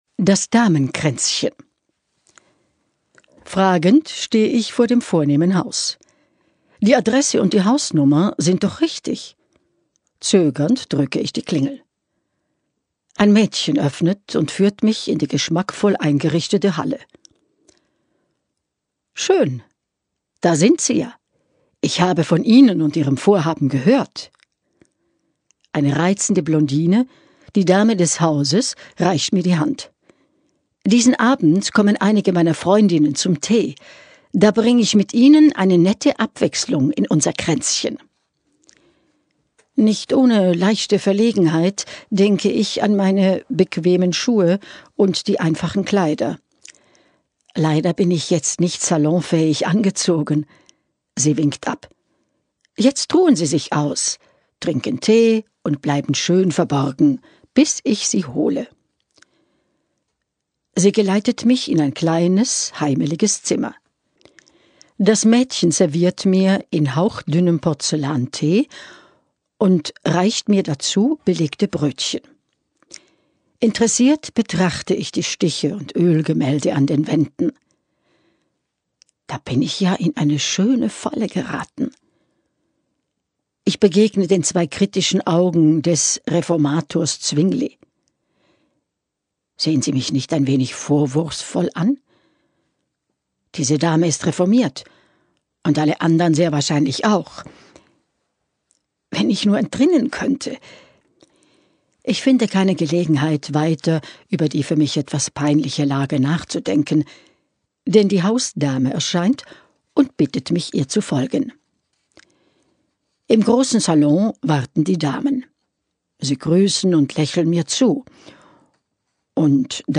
Die Erzählerin